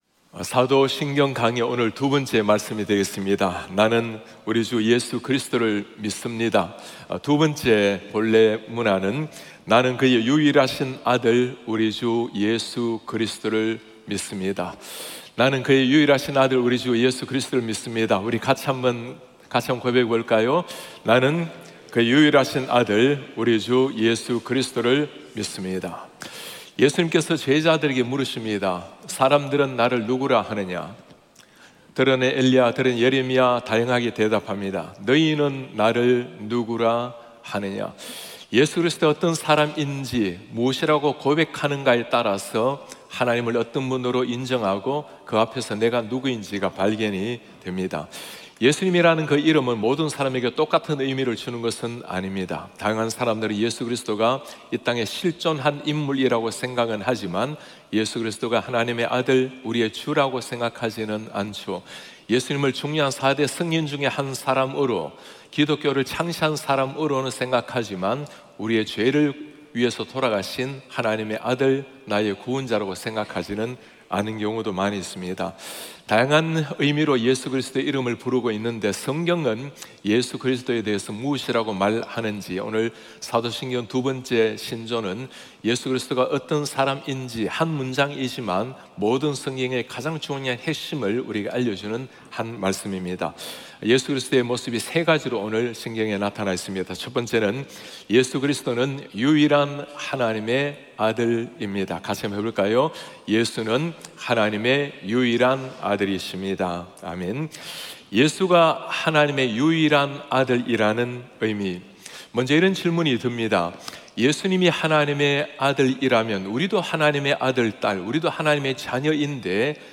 예배: 토요 새벽